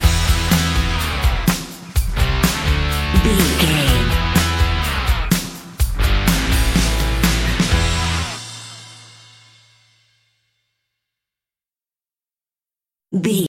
Mixolydian
electric guitar
bass guitar
drums
hard rock
aggressive
energetic
intense
nu metal
alternative metal